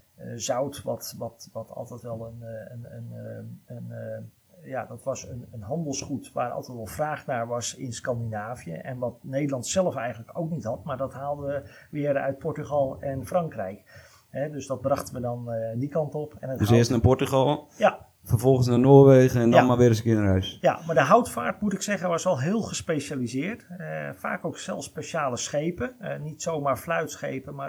Electrical Whistle in Recording
The result will be a mono track without the whistling.
7. Apply gentle noise reduction - better to leave a bit of hiss than to create “over-processing” artifacts.